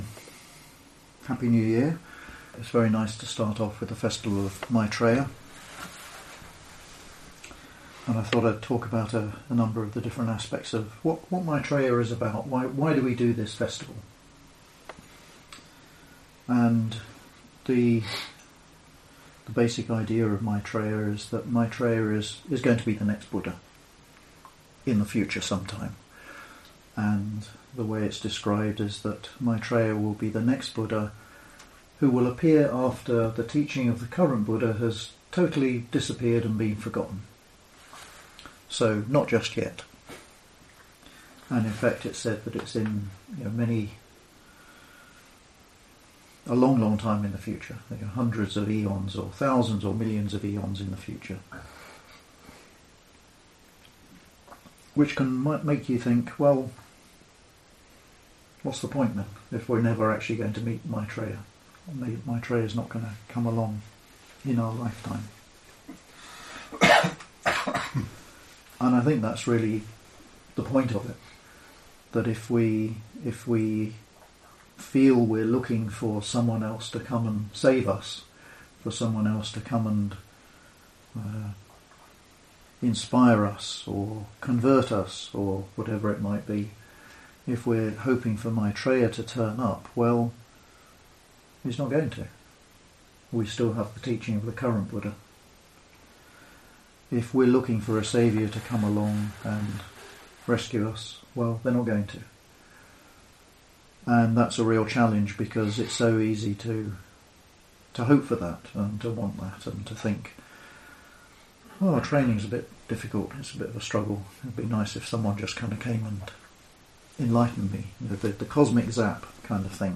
A talk given at the Festival of Maitreya on New Year's Day, discussing aspects of the teachings relating to 'The Buddha who is yet to come'.